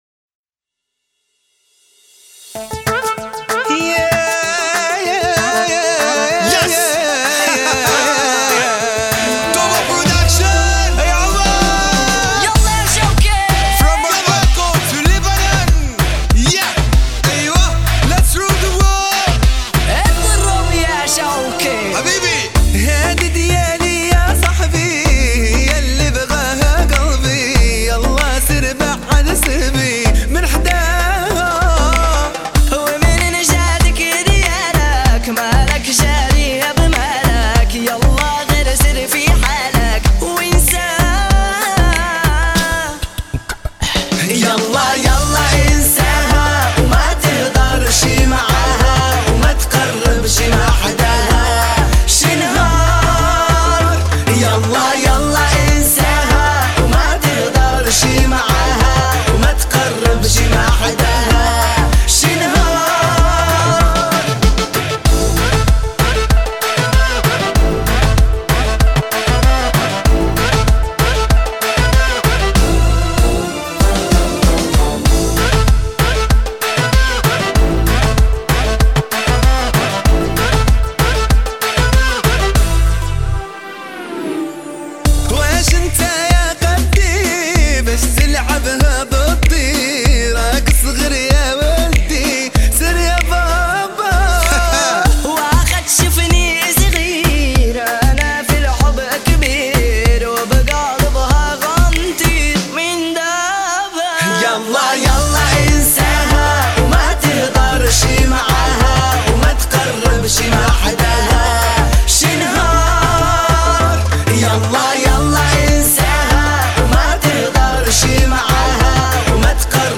отличается яркими аранжировками и гармоничным вокалом